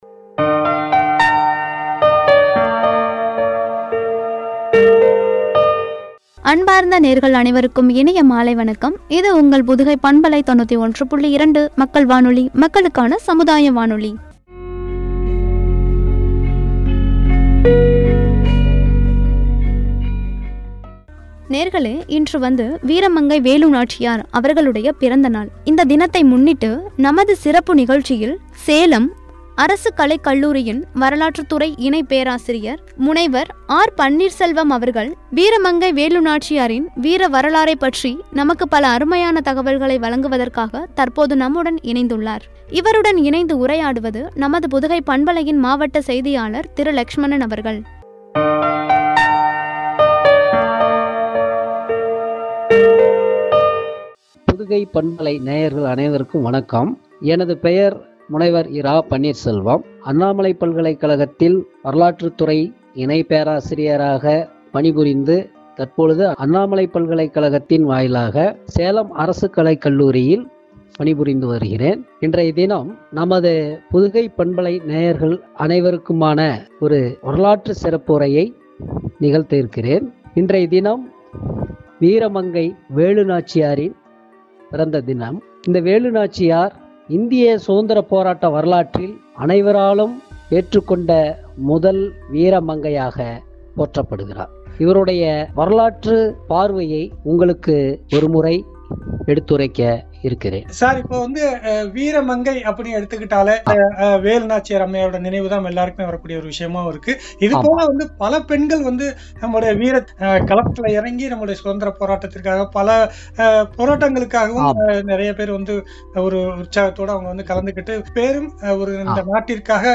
தேசப்பற்றும்” என்ற தலைப்பில் வழங்கிய உரையாடல்.